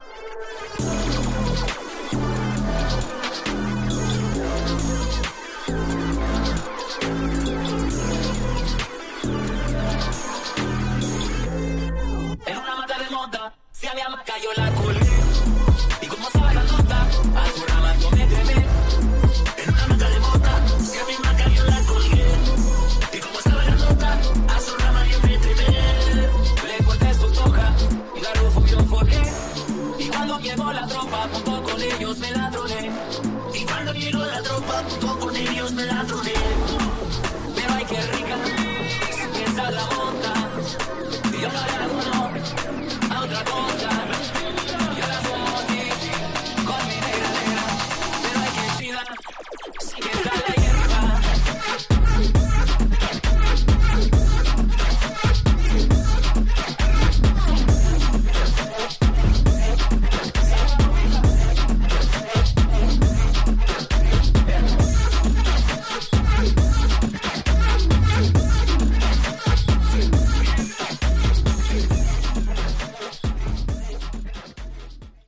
Tags: Cumbia
Super bailables: cumbia con raps, rock, housito.